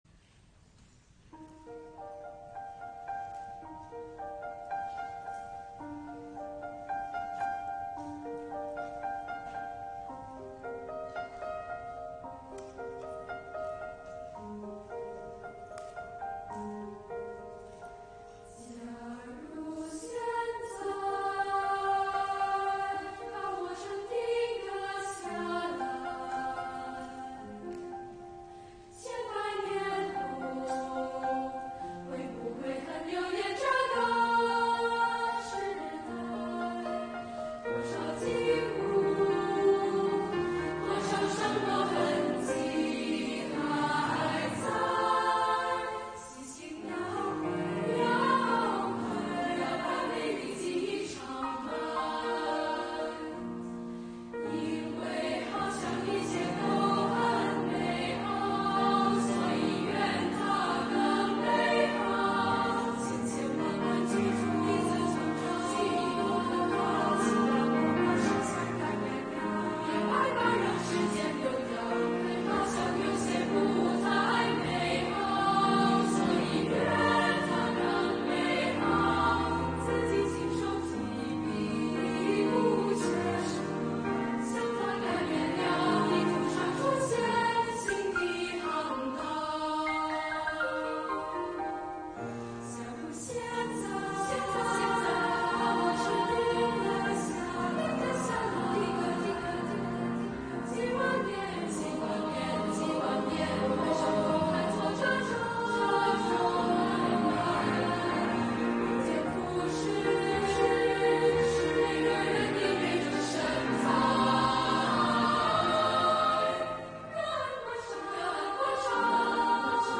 聲部：SSA